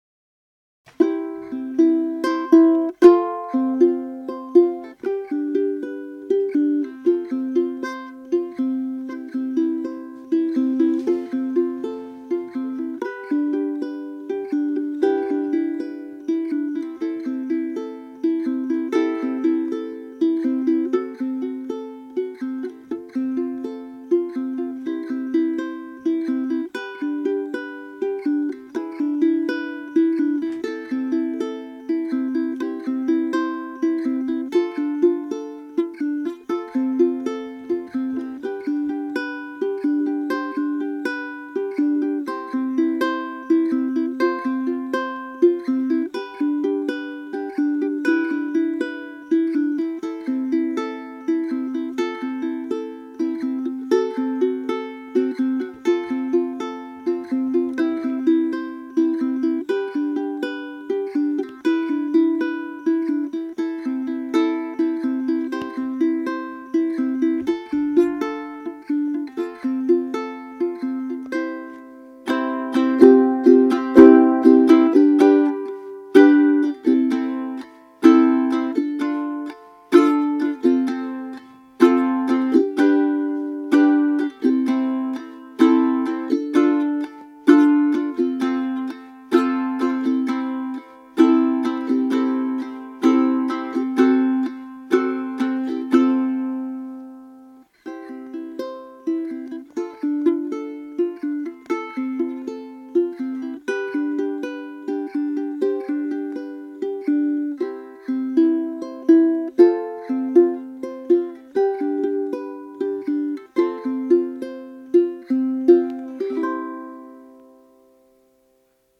Tenor Ukulele model
The Standard Tenor Ukulele blends clarity, projection, and playability in a body that’s slightly larger than the concert uke—offering a deeper tone and greater resonance while keeping that classic Hawaiian charm.
Strings 4 (standard GCEA tuning)
Hear the crisp tones and balanced warmth of the standard Tenor Ukulele.
Tenor-Sound-Sample.mp3